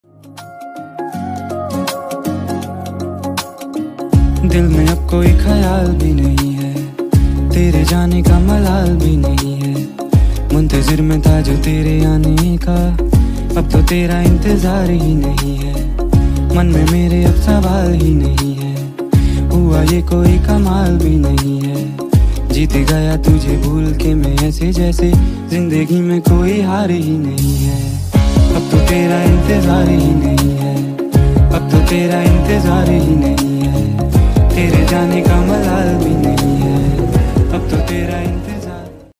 best flute ringtone download | dance song ringtone
mass ringtone